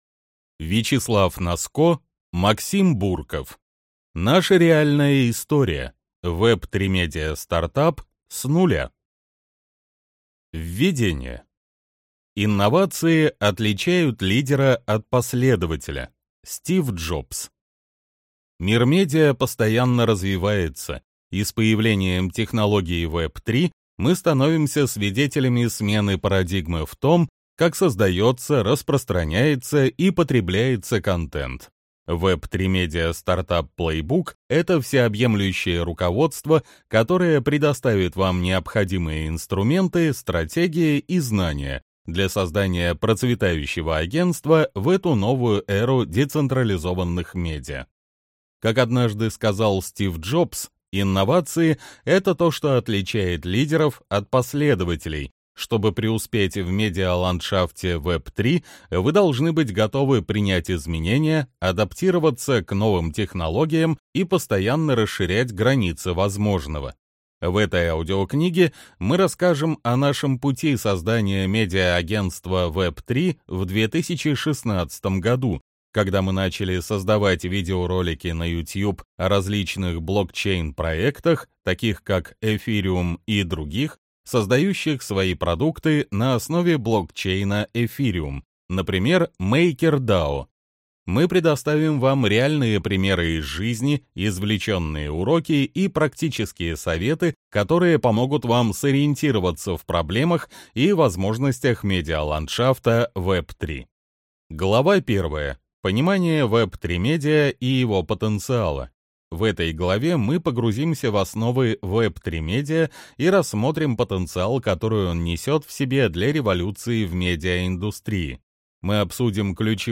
Аудиокнига Наша реальная история: Web3 Media Startup с нуля.